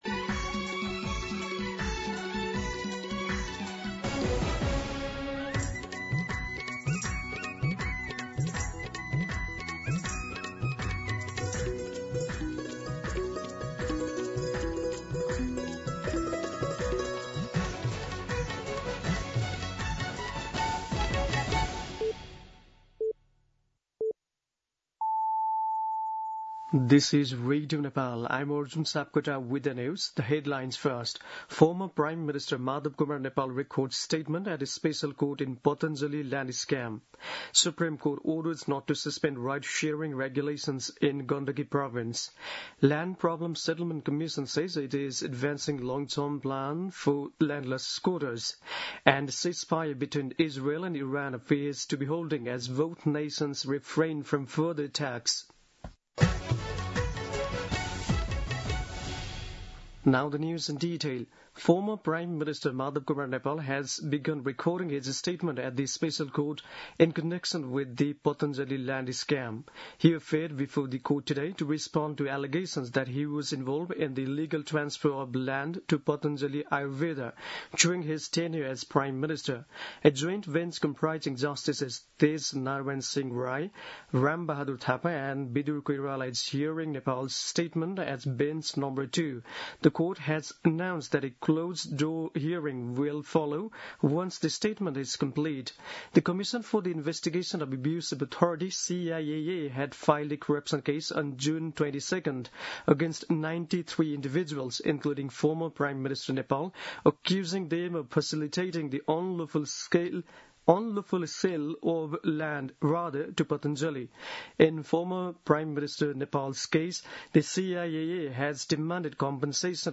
2pm-English-News-11.mp3